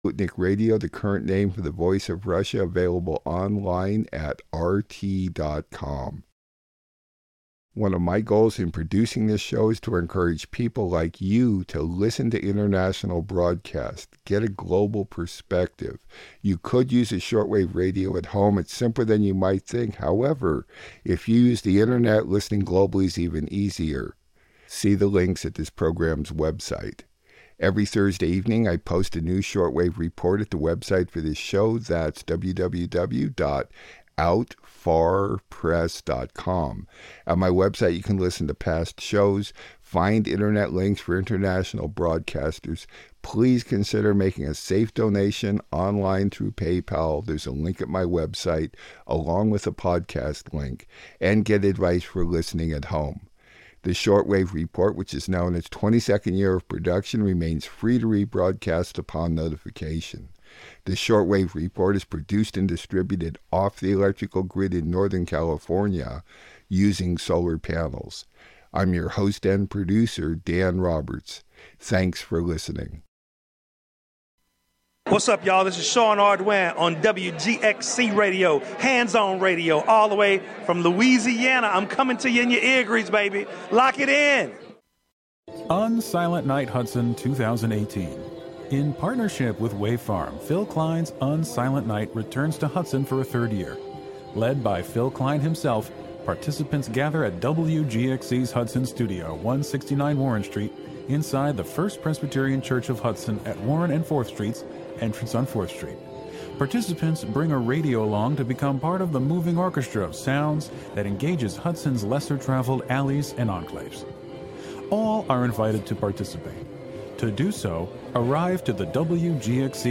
"All Together Now!" is a daily news show covering...